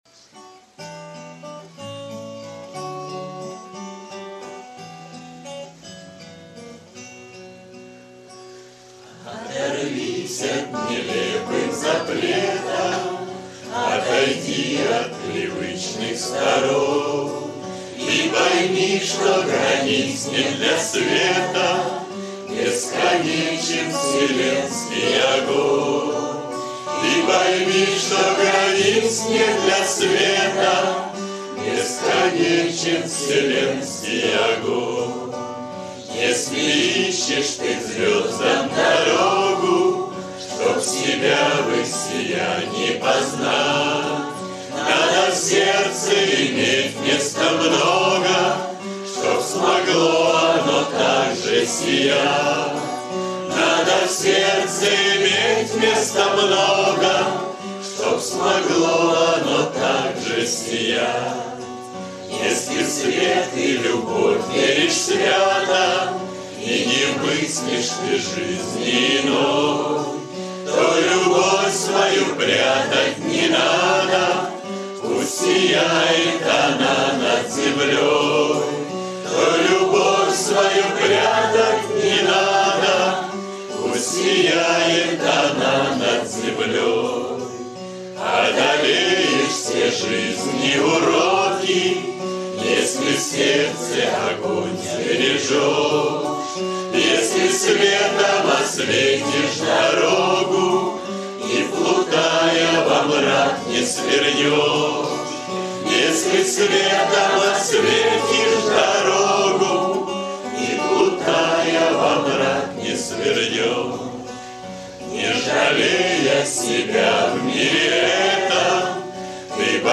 кавер-версия
акапелла